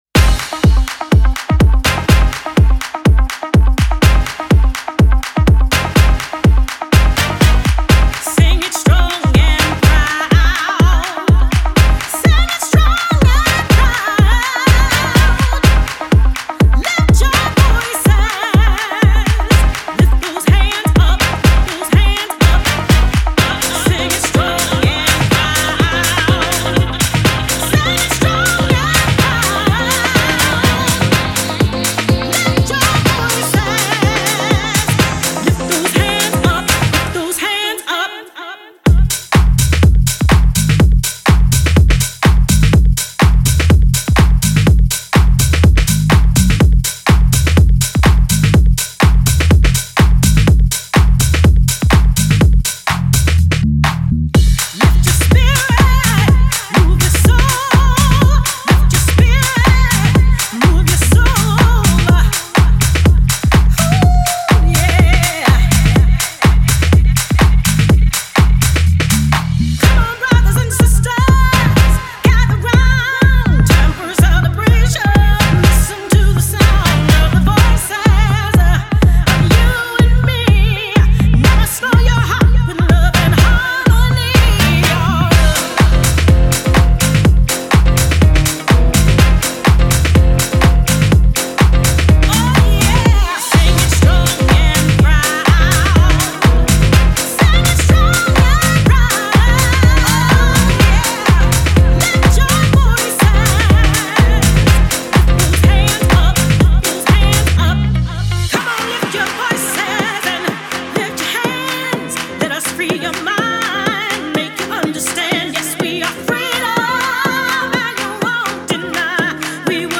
o corte vocal superalimentado
a produção de riffs de piano
o vocal poderoso
faz uma trilha sonora alegre e unificadora.